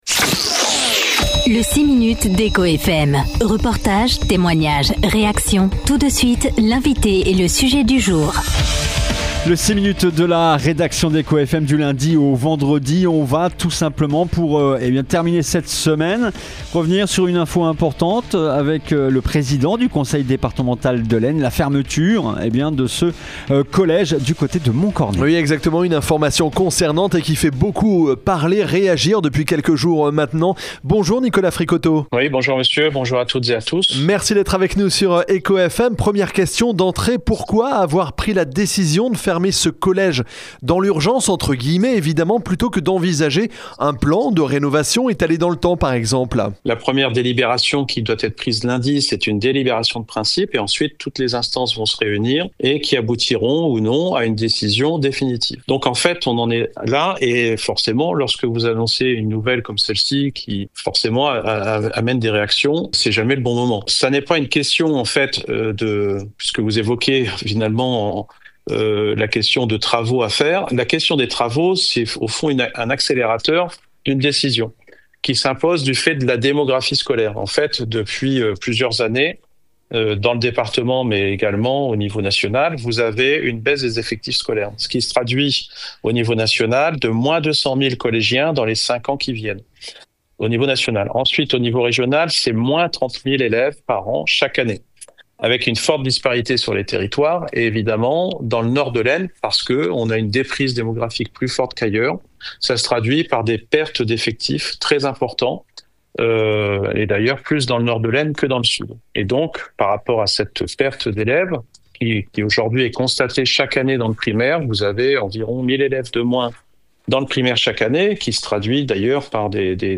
Ce vendredi 17 octobre, le président du conseil départemental de l’Aisne, Nicolas Fricoteaux, était l’invité du 6 minutes de la rédaction d’Echo FM.